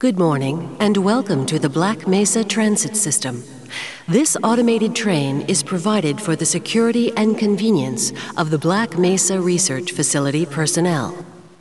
Женский голос для оповещений